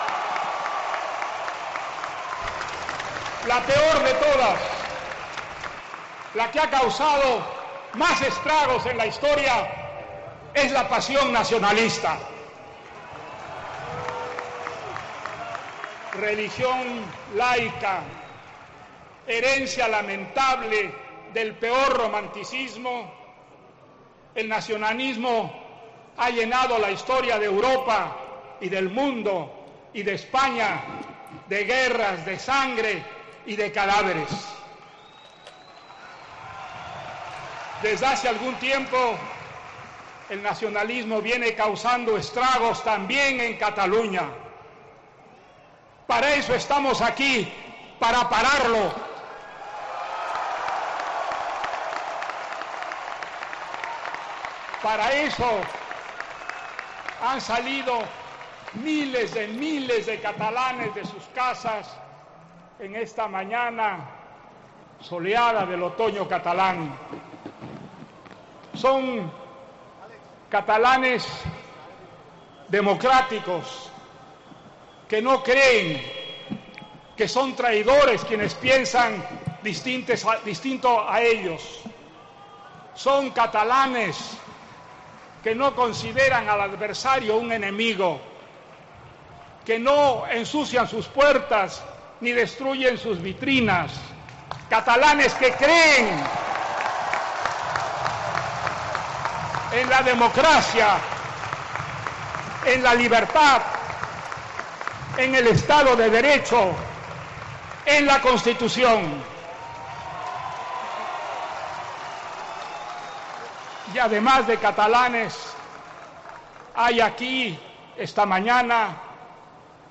Vargas Llosa ha participado este domingo en la manifestación en Barcelona a favor de la unidad de España y en contra de la independencia de Cataluña, una protesta masiva que va desde la plaza Urquinaona hasta la estación de Francia, con banderas españolas, catalanas y europeas bajo el lema "¡Basta! Recuperemos la sensatez".